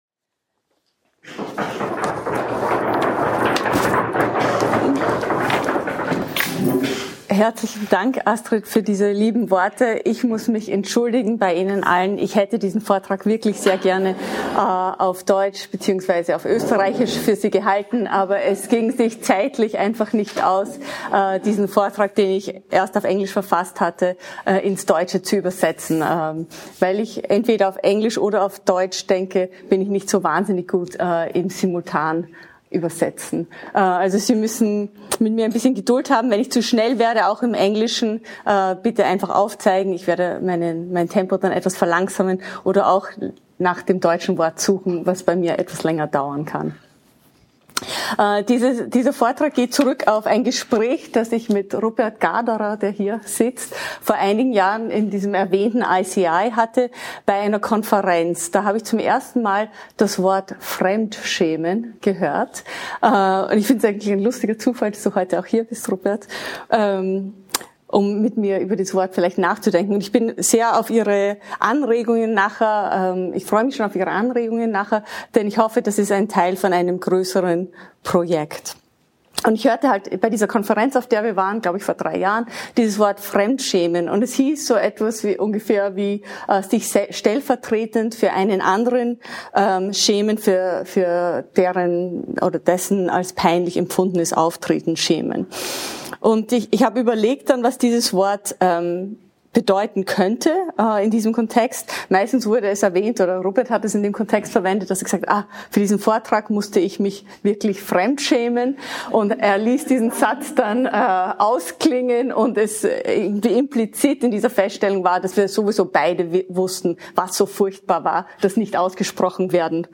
In this talk, I draw on the literature regarding shame to show how »fremdschaemen« articulates a desire to introduce distance in our ever-mediated and overly intimate interactions.